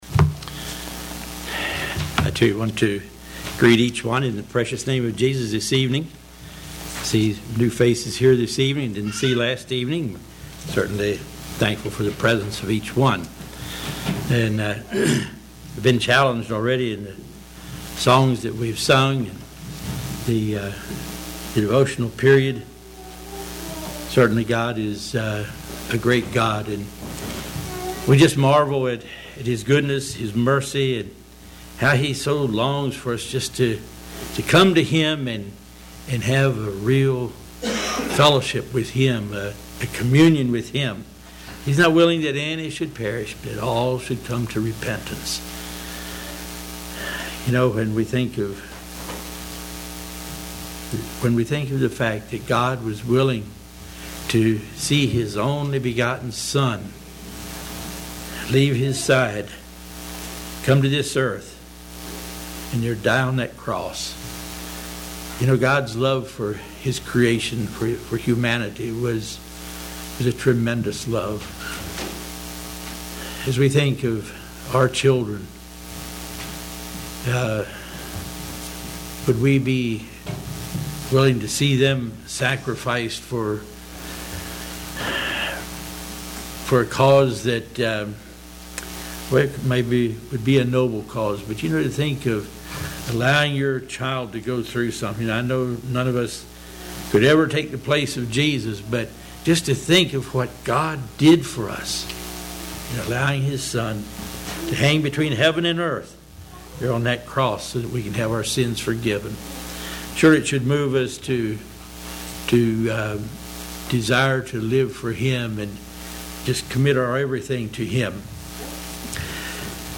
Sermons
Ridge View | Tent Meetings 2023